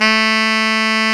Index of /90_sSampleCDs/Roland L-CD702/VOL-2/SAX_Tenor mf&ff/SAX_Tenor ff
SAX TENORF0C.wav